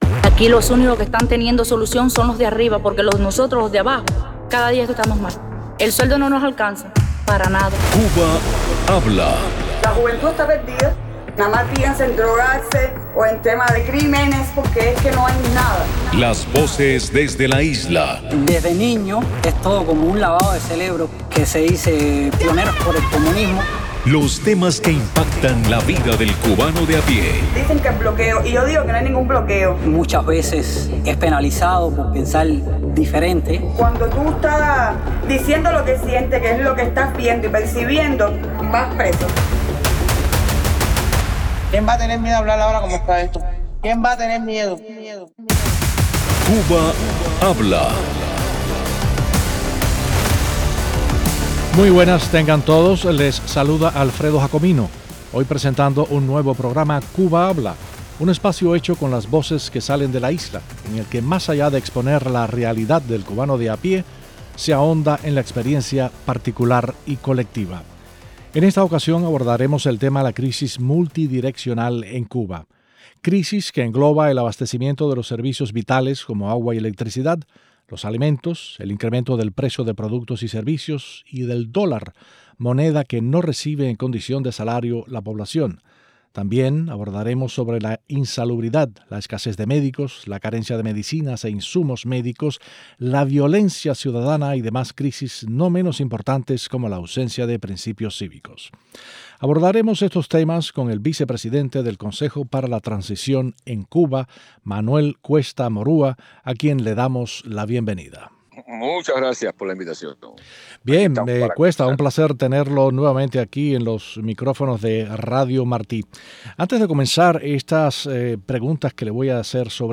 Ciudadanos cubanos expresan desde la isla las formas en que se manifiesta la crisis multidireccional en el país.